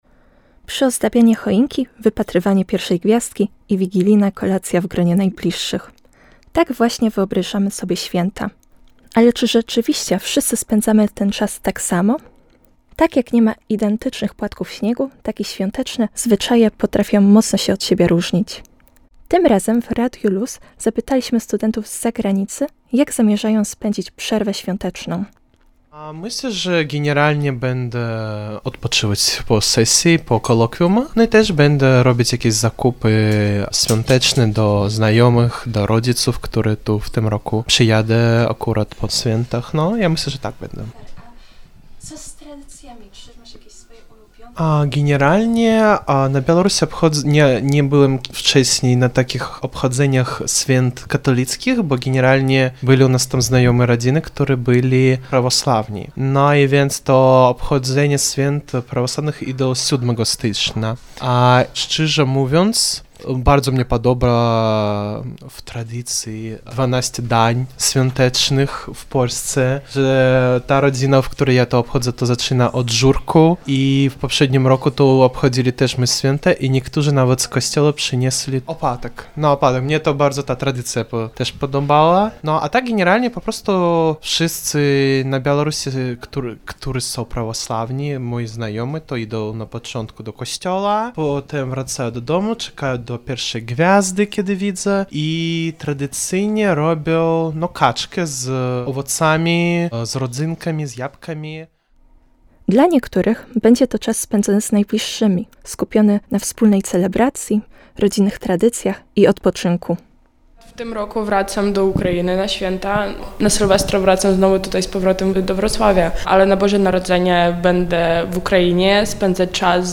O swoich planach na przerwę świąteczną opowiedzieli Radiu LUZ studenci z zagranicy i wymian międzynarodowych.